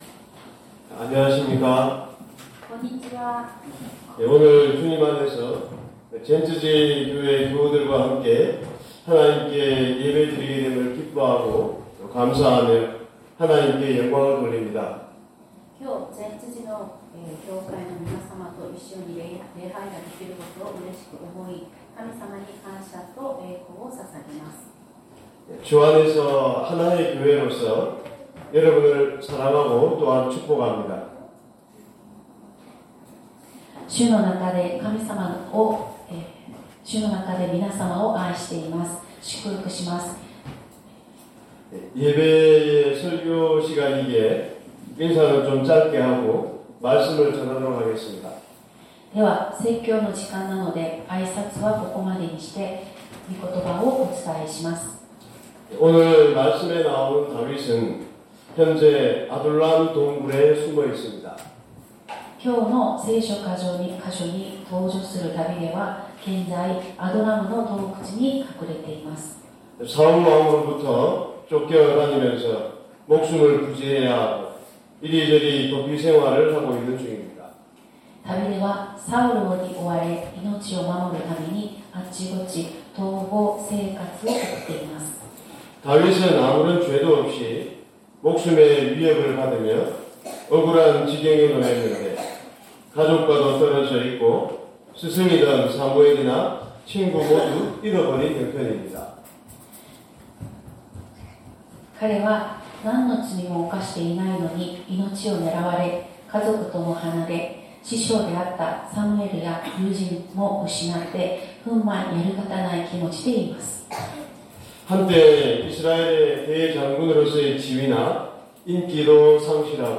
説教アーカイブ 2025年02月09日朝の礼拝「アドラムの洞窟」
礼拝説教を録音した音声ファイルを公開しています。